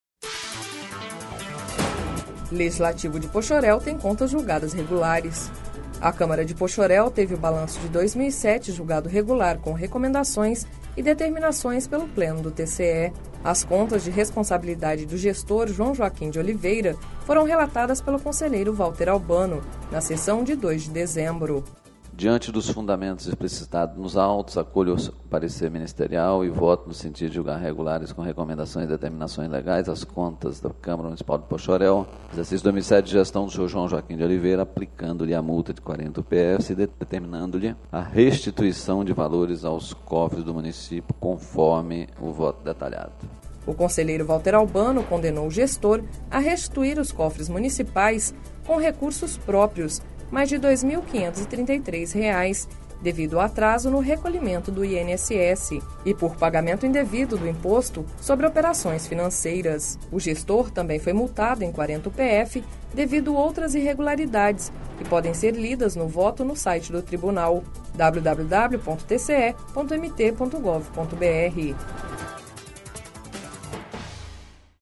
Sonora: Valter Albano – conselheiro do TCE- MT